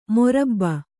♪ morabba